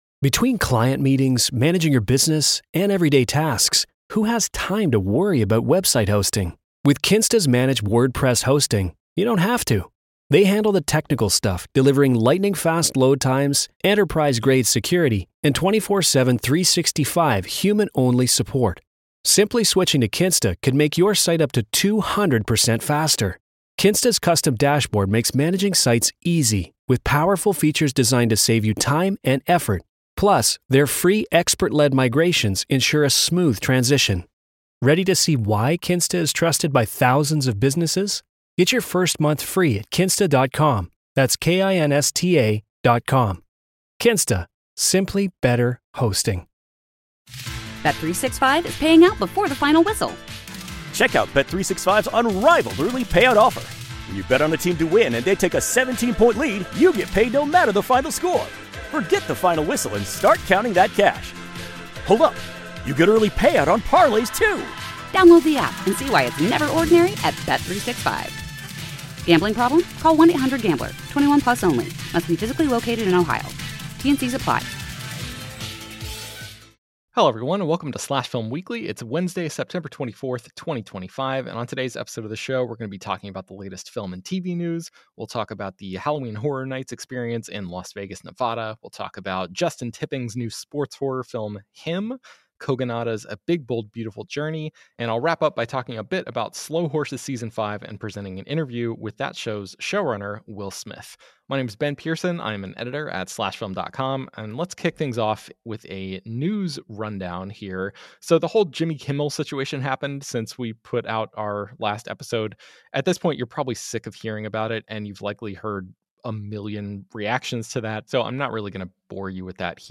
In The News: Keri Russell Reunites With J.J. Abrams For ‘Star Wars: Episode 9’ In Our Feature Presentation: Kevin Feige Explains How They Planned ‘Ant-Man and The Wasp’ Alongside ‘Infinity War,’ the Disney Streaming Service and More [Interview]